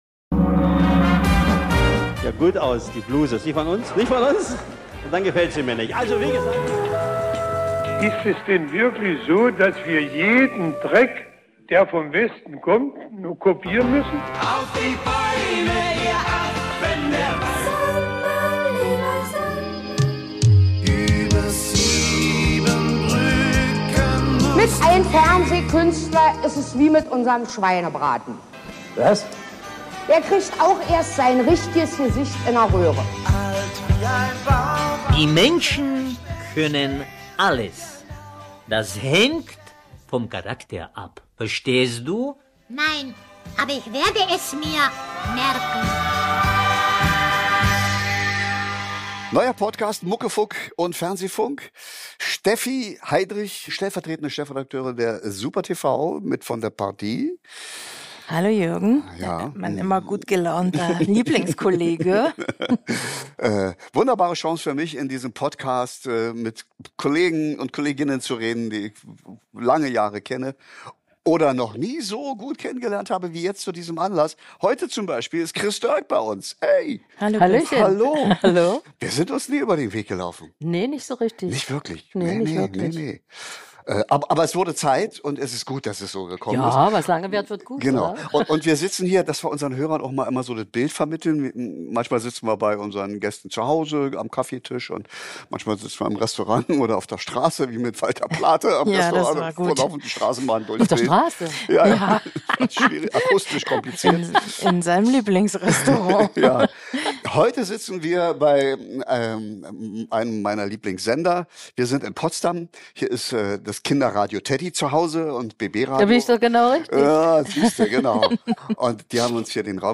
Genießen Sie das Gespräch mit einer gut gelaunten Chris Doerk.